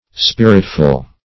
Search Result for " spiritful" : The Collaborative International Dictionary of English v.0.48: Spiritful \Spir"it*ful\, a. Full of spirit; spirited.